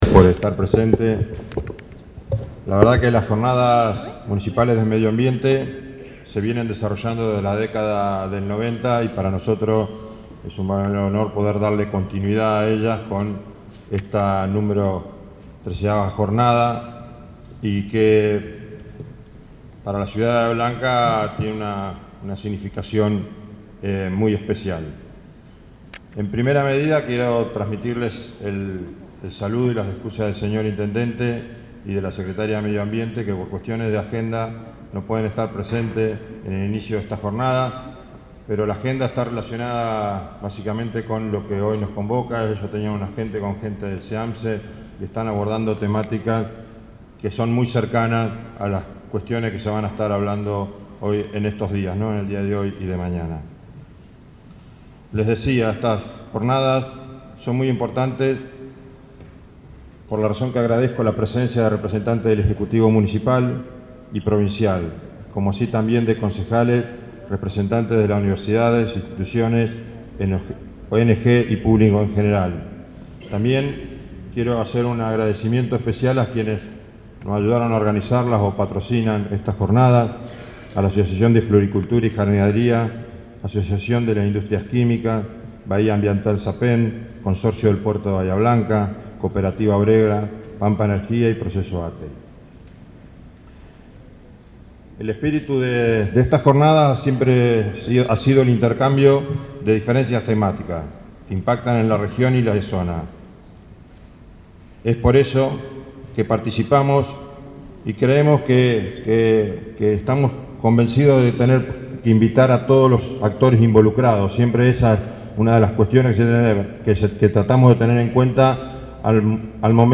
La apertura del encuentro fue encabezada por Fabio Pierdominici, secretario de Gobierno, acompañado por Nicolás Vitalini, presidente del Concejo Deliberante y Gabriela Rozas Dennis, directora de Gestión Ambiental.
Pierdominci-XIII-jornadas-municipales-de-medioambiente-05-06.mp3